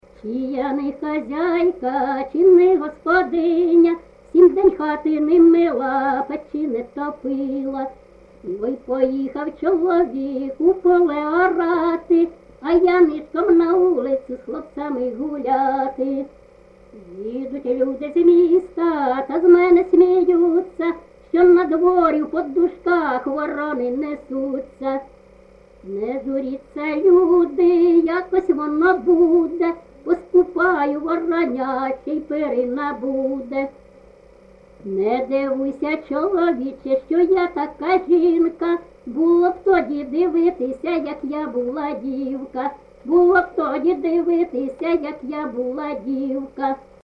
ЖанрЖартівливі
Місце записус-ще Михайлівське, Сумський район, Сумська обл., Україна, Слобожанщина